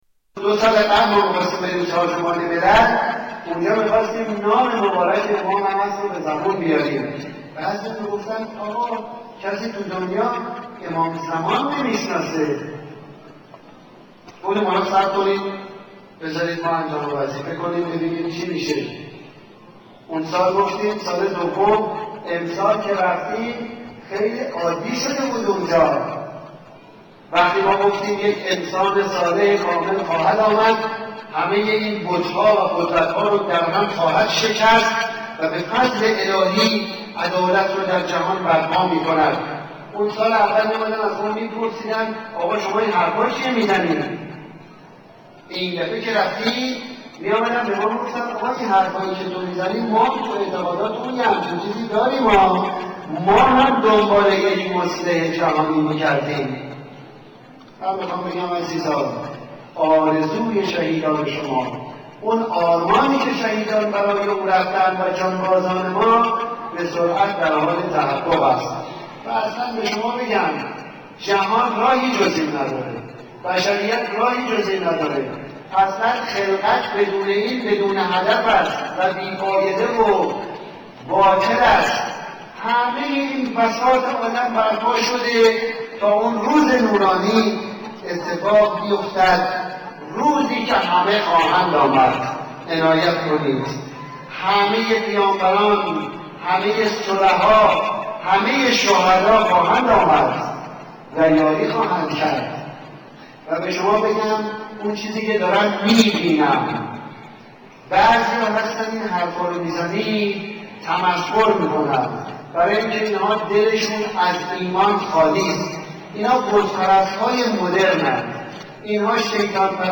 Another Controversial speech BOZGHALEH